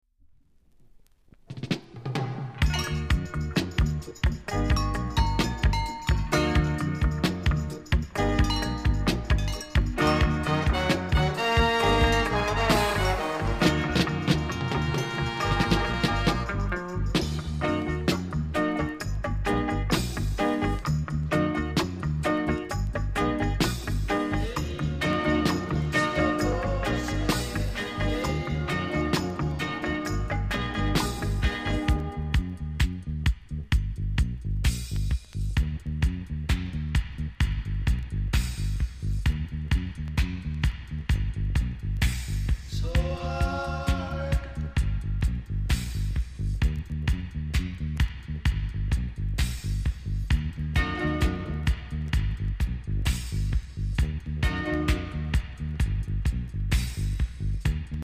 コメント HEAVY ROOTS!!RARE!!※極僅かに反りがあります。